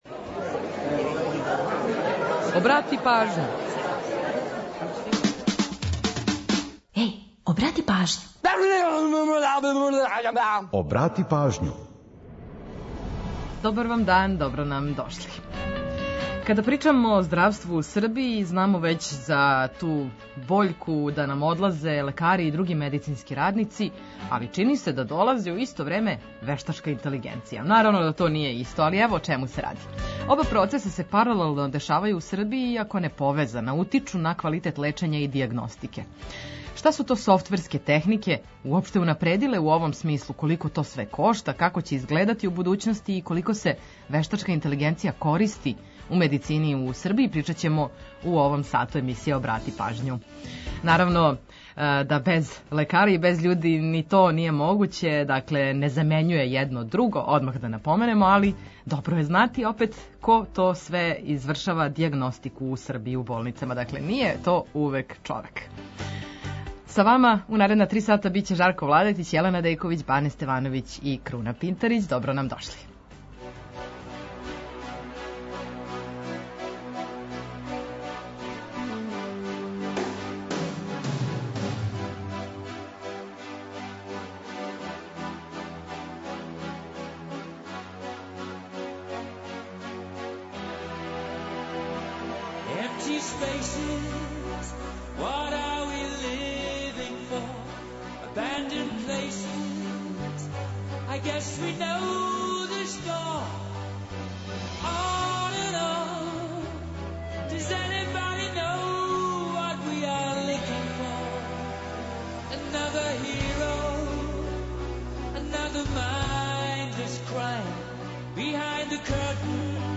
Чућете „Приче о песмама”, пола сата музике из Србије и региона, а упозоравамо и на евентуалне саобраћајне гужве.